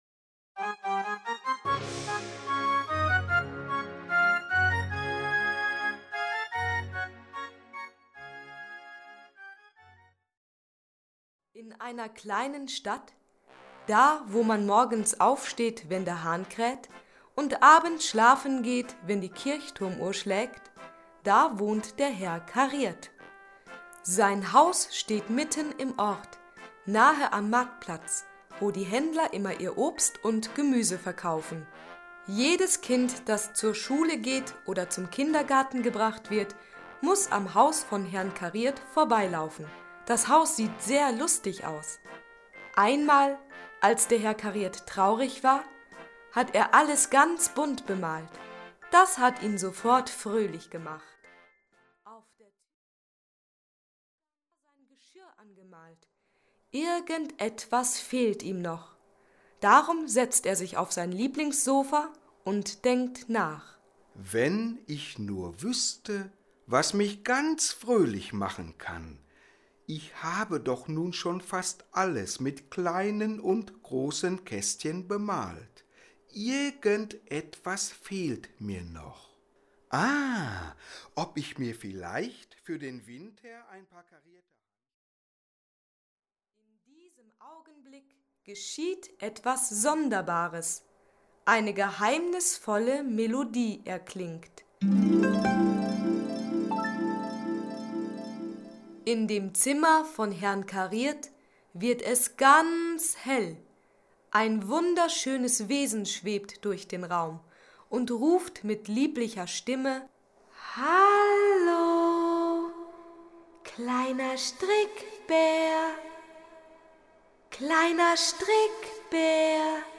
Ein Hörbuch mit Musik: